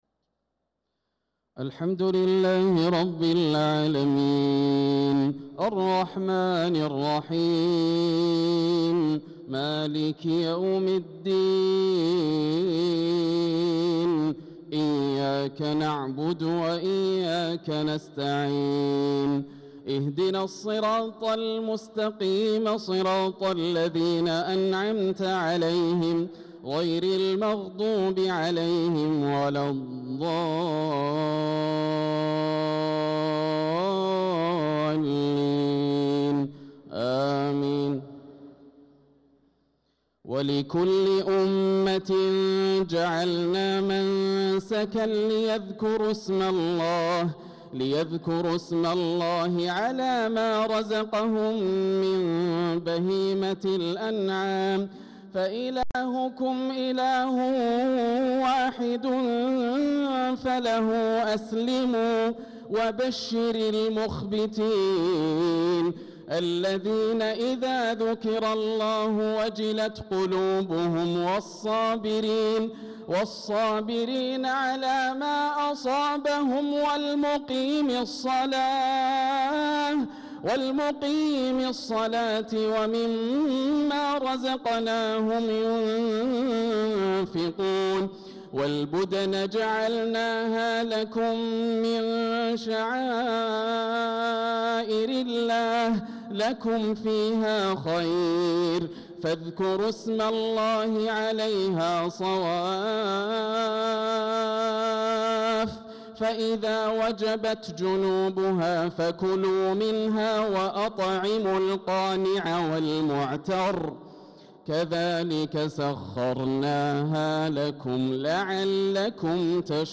صلاة العشاء للقارئ ياسر الدوسري 10 ذو الحجة 1445 هـ
تِلَاوَات الْحَرَمَيْن .